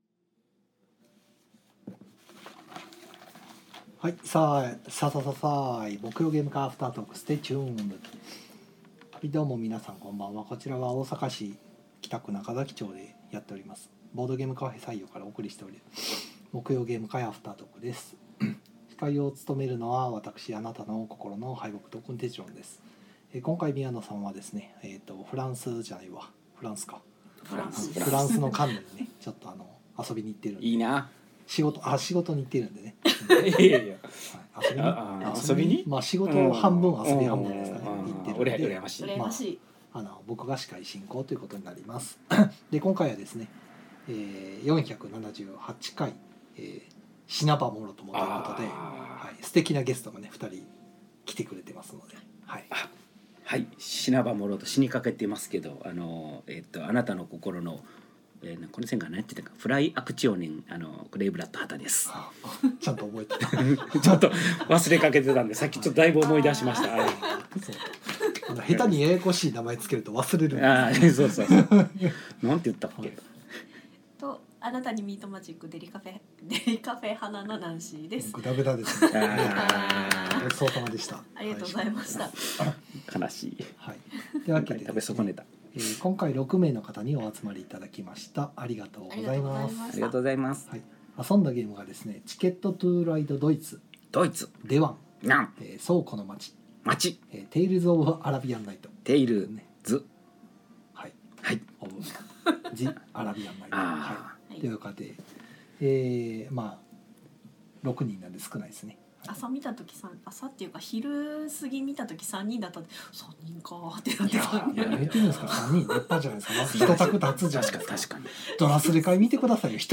ゲーム会の話や、近況などをダラダラと生配信で垂れ流したものを鮮度そのままノーカットでパッケージング！（podcast化）